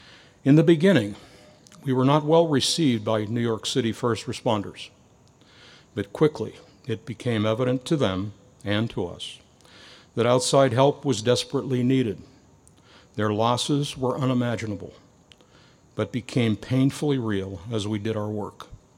This morning, members of the IUP community gathered in the Oak Grove to pay tribute to the events of the September 11 attacks in New York, Washington D.C. and Shanksville.